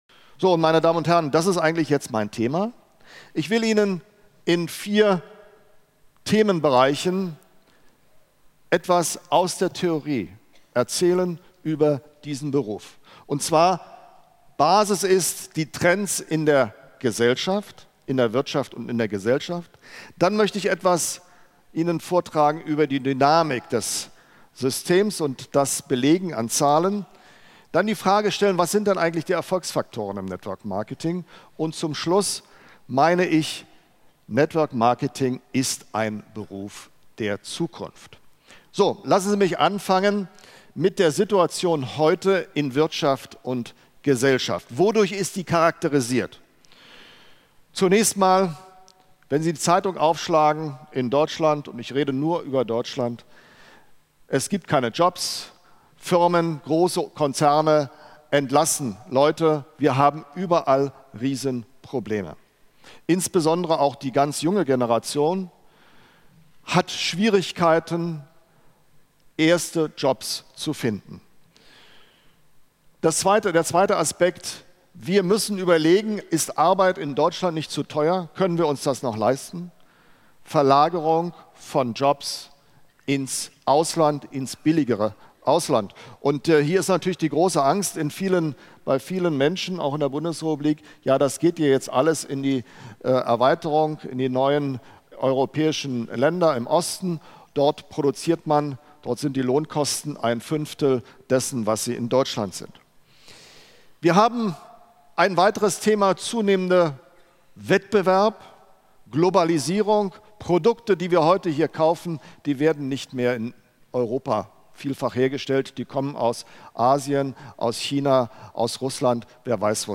Live Mitschnitt